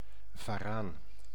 Ääntäminen
Ääntäminen Tuntematon aksentti: IPA: /vaˈraːn/ Haettu sana löytyi näillä lähdekielillä: hollanti Käännös Substantiivit 1. monitor lizard 2. leguaan Suku: m .